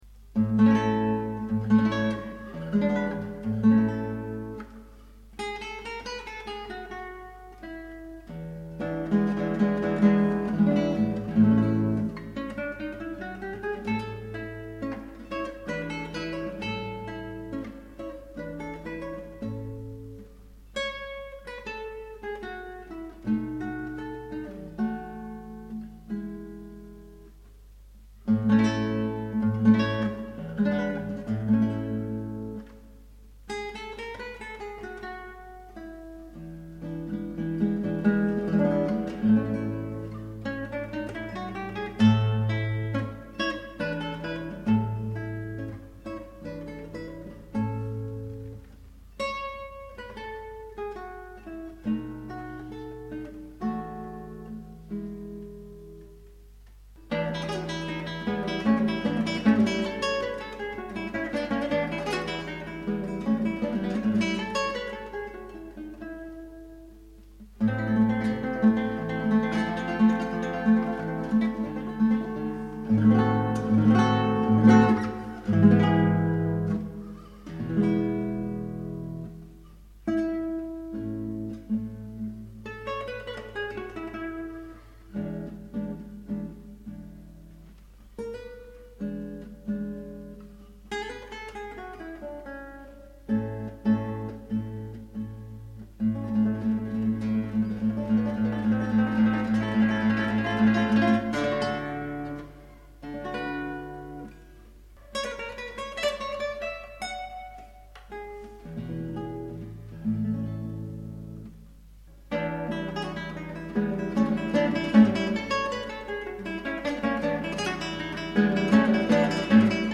Guitare Classique
Ah ton jeu et très propre
Sérieusement, c'est excellent, très brillant, très nuancé !
Nota : je n'ai que la voie gauche sur mon PC... y-aurait-il eu un petit problème technique lors de la prise de son ?